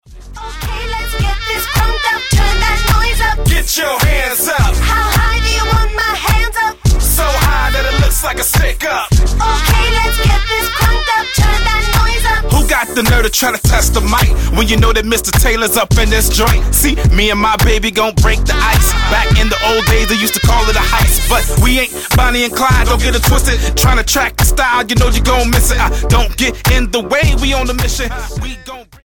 Rap duo
Style: Hip-Hop